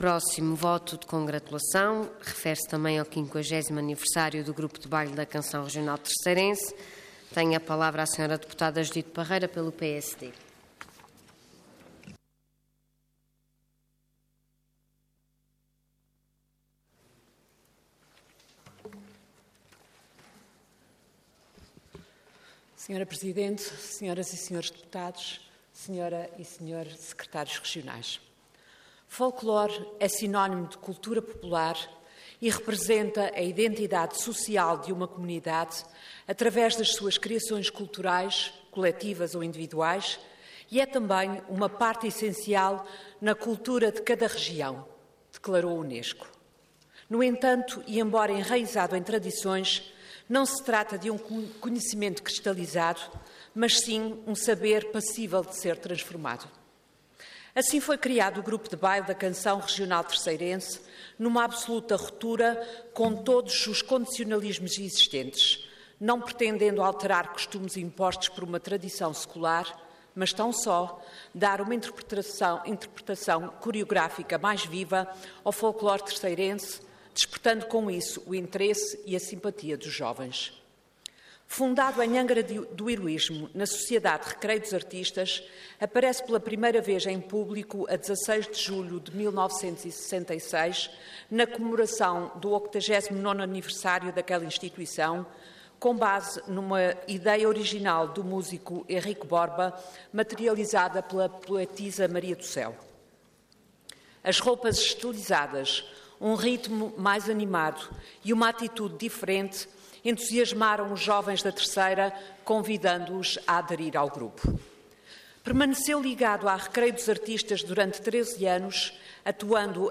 Detalhe de vídeo 7 de setembro de 2016 Download áudio Download vídeo Processo X Legislatura 50.º Aniversário do Grupo de Baile da Canção Regional Terceirense Intervenção Voto de Congratulação Orador Judite Parreira Cargo Deputada Entidade PSD